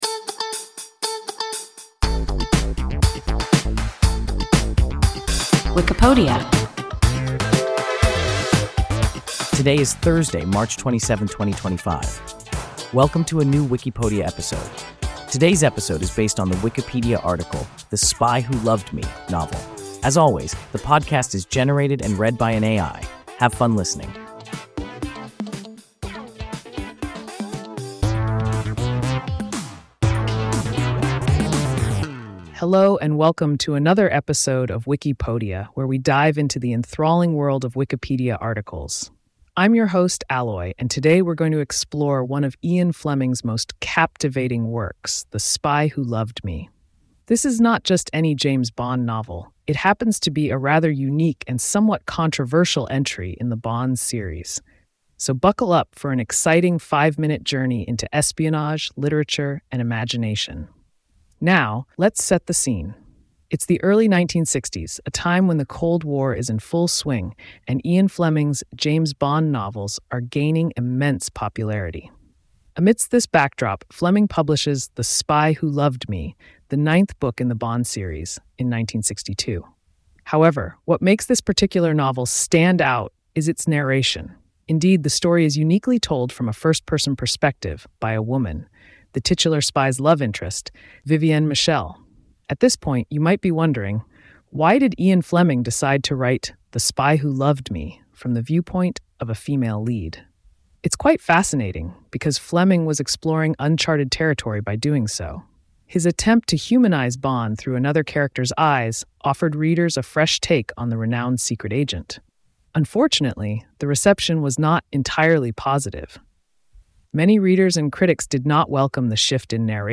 The Spy Who Loved Me (novel) – WIKIPODIA – ein KI Podcast